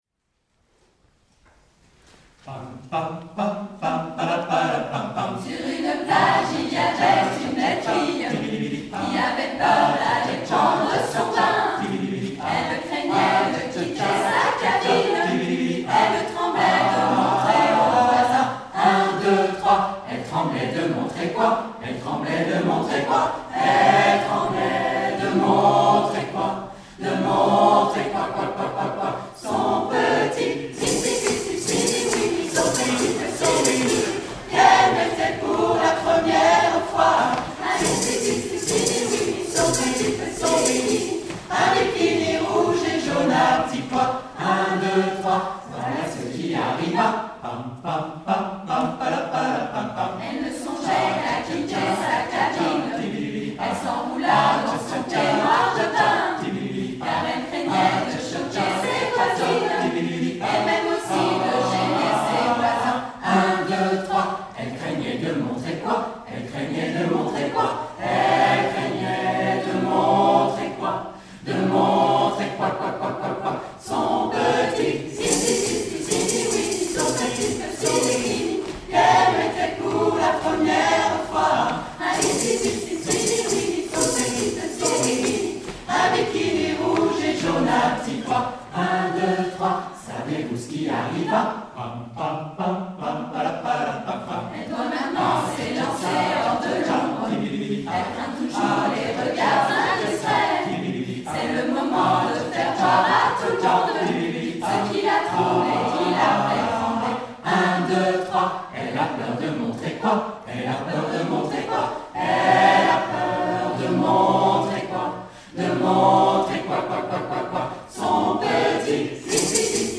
HISTORIQUE DES CHANTS HARMONISES & INTERPRETES DEPUIS 2005